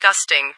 - New ATIS Sound files created with Google TTS en-US-Studio-O
Gusting.ogg